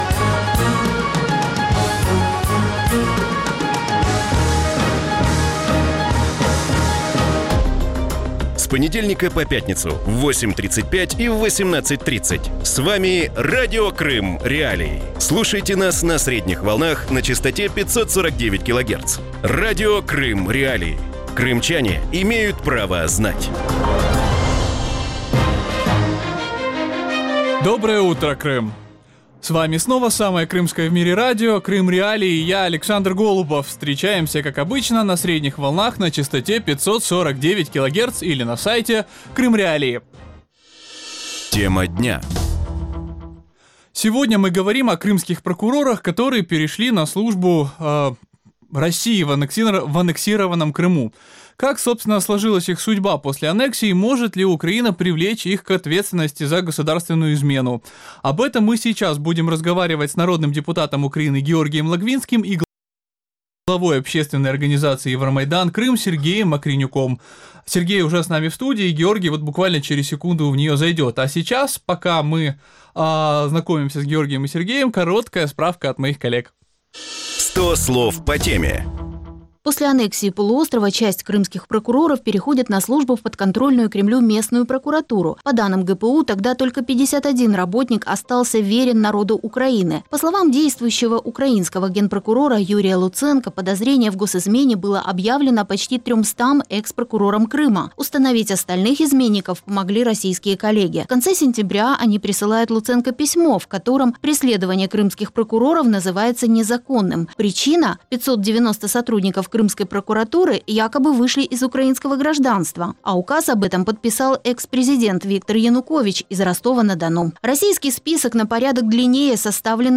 Утром в эфире Радио Крым.Реалии говорят о крымских прокурорах, которые перешли на службу России в аннексированном Крыму.